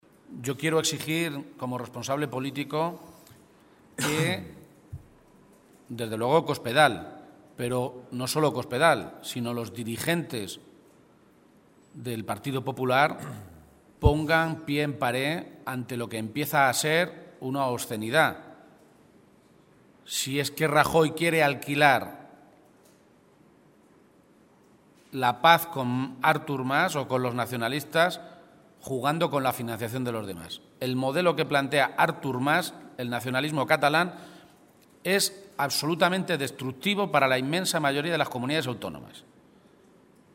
García-Page se pronunciaba de esta manera, a preguntas de los periodistas, en una comparecencia ante los medios de comunicación, esta mañana, en Toledo, en la que insistía en que esperaba que “Rajoy no tenga tentaciones de pactar, ni por debajo, ni por encima de la mesa, porque la financiación que pretende Cataluña rompe el concepto de solidaridad territorial”.
Cortes de audio de la rueda de prensa